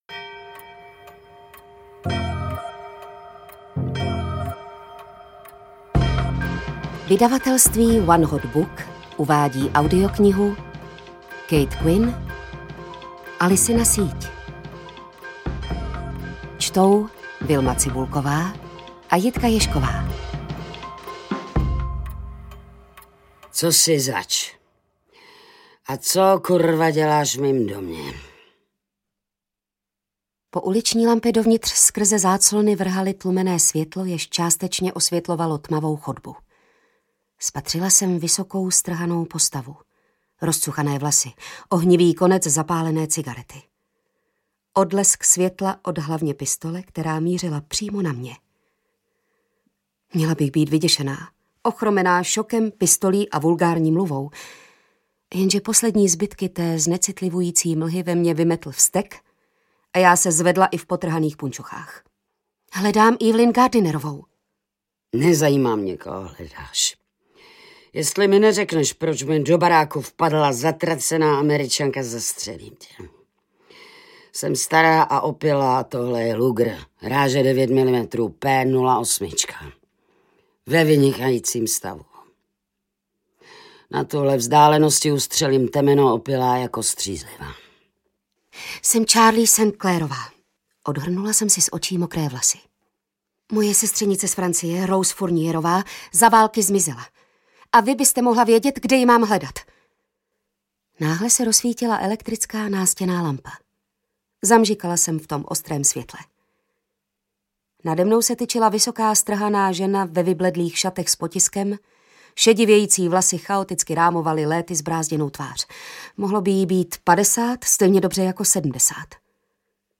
Audio knihaAlicina síť
Ukázka z knihy
• InterpretVilma Cibulková, Jitka Ježková